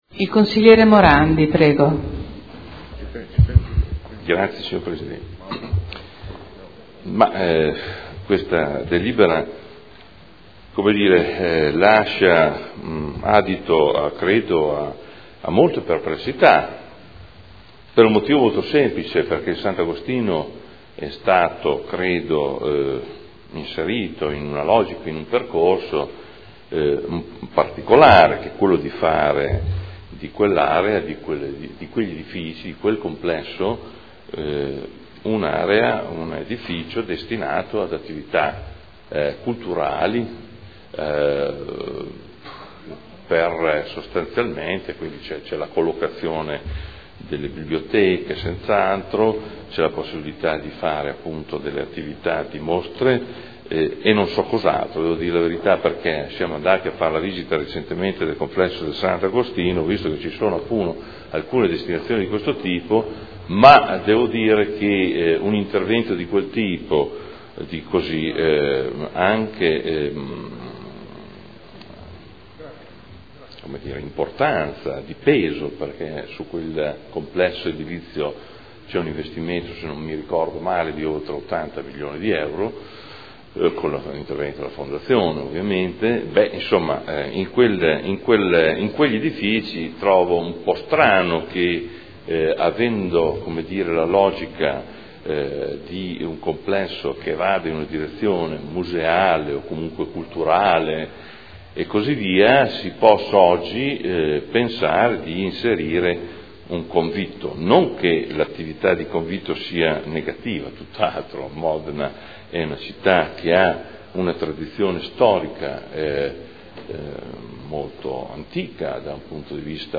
Seduta del 9 gennaio. Proposta di deliberazione: Nulla osta al rilascio del permesso di costruire in deroga agli strumenti urbanistici presentato da Fondazione Cassa di Risparmio di Modena per realizzare un convitto all’interno del nuovo polo culturale nel complesso immobiliare Sant’Agostino (Ex Ospedale Civile).